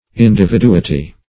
Meaning of individuity. individuity synonyms, pronunciation, spelling and more from Free Dictionary.
Search Result for " individuity" : The Collaborative International Dictionary of English v.0.48: Individuity \In`di*vi*du"i*ty\, n. [L. individuitas.]